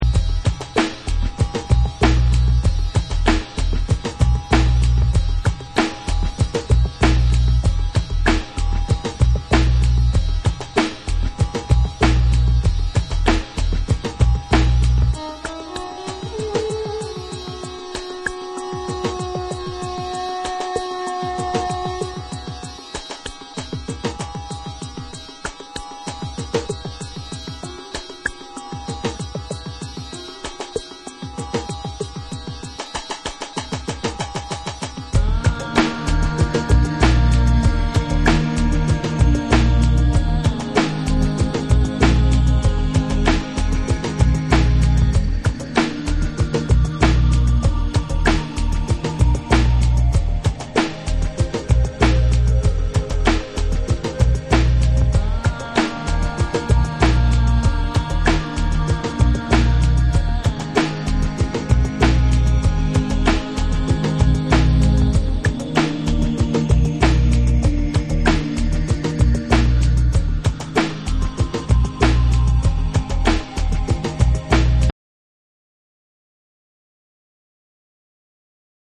パーカッシヴなブレイクビーツとハウシーな上音が気持ち良いSAMPLE2
BREAKBEATS / TECHNO & HOUSE